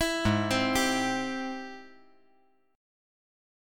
Ab+M7 Chord
Listen to Ab+M7 strummed